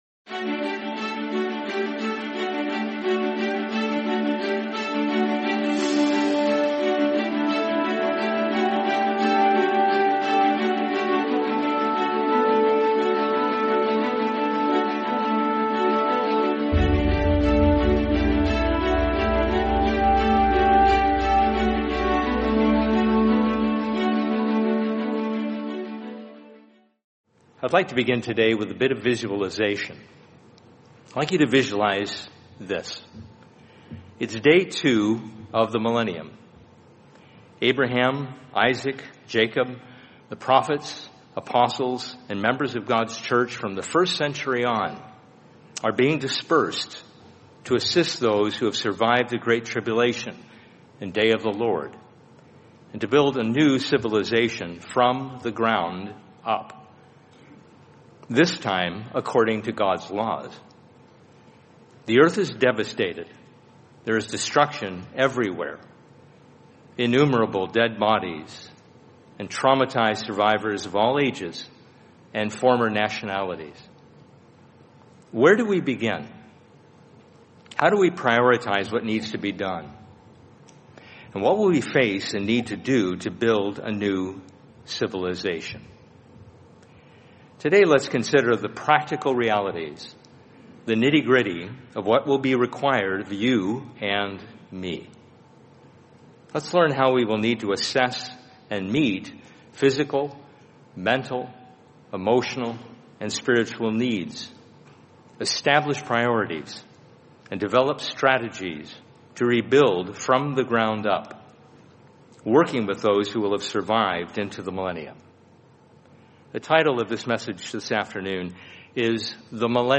Feast of Tabernacles 2025: The Millennium From the Ground Up | Sermon | LCG Members